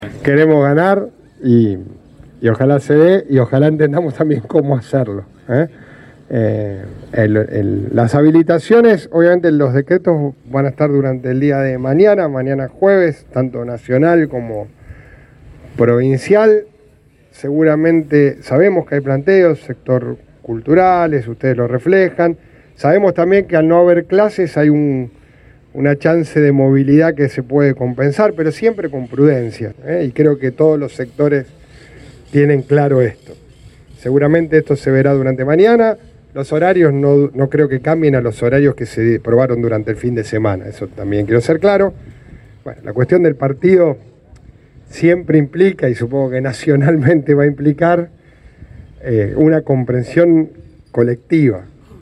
Así lo aseguró durante el mediodía del miércoles, el Intendente Pablo Javkin, quien sostuvo que antes del fin de semana se conocerán nuevas aperturas, aunque la palabra prudencia estuvo en casi todo su discurso.